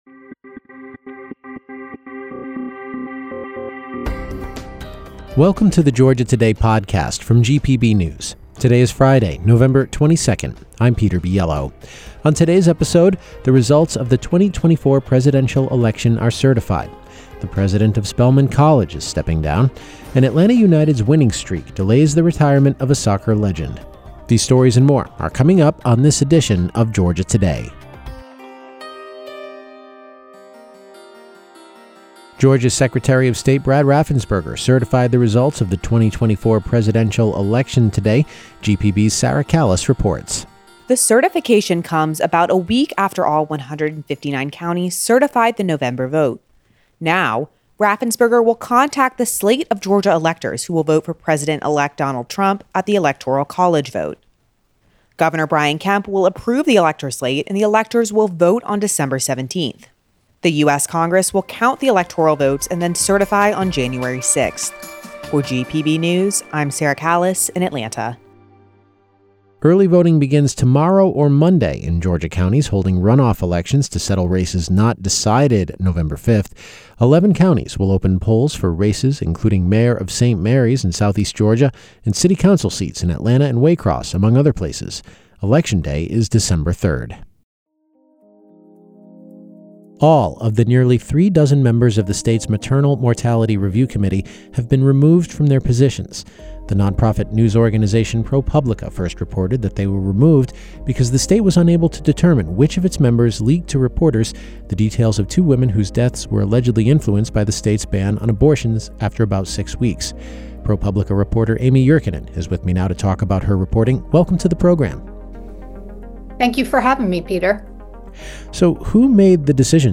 Daily News